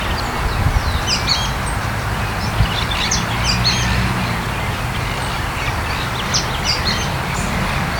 Pijuí Frente Gris (Synallaxis frontalis)
Nombre en inglés: Sooty-fronted Spinetail
Fase de la vida: Adulto
Condición: Silvestre
Certeza: Vocalización Grabada